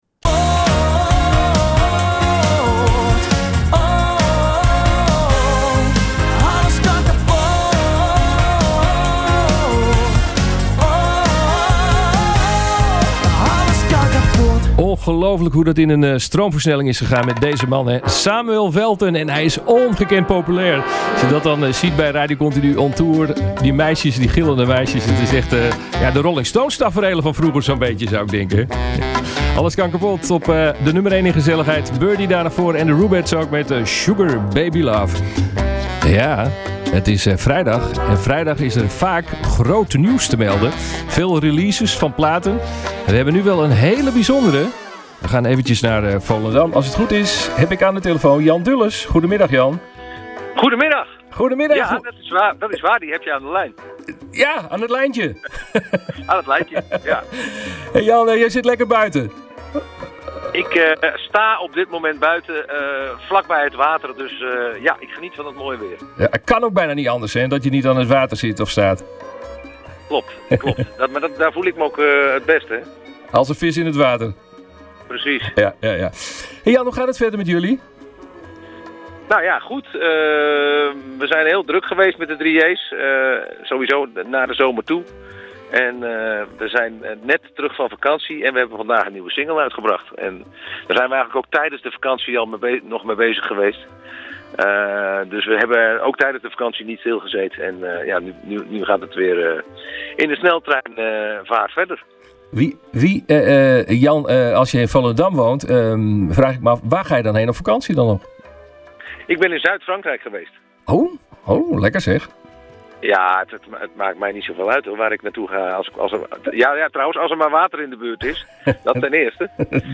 Deze vrijdagmiddag belden we met Jan Dulles over de nieuwe samenwerking met Rowwen Hèze.